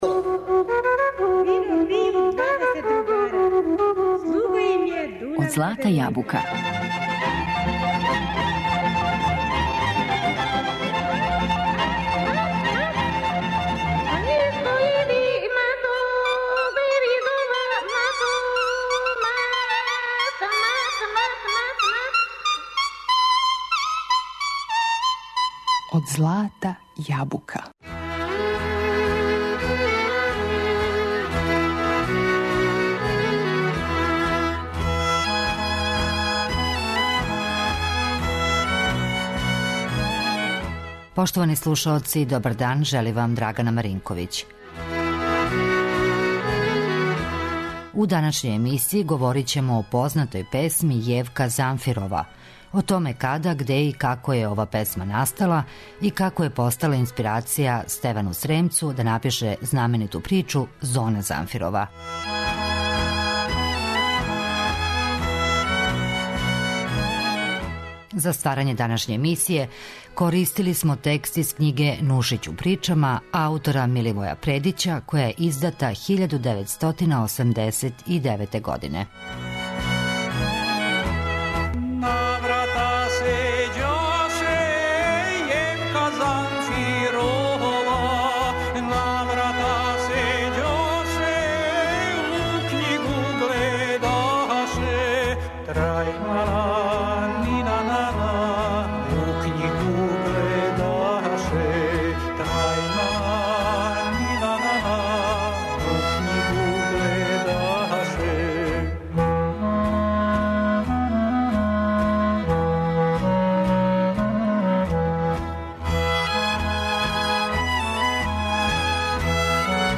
Емисија је резервисана за изворну народну музику.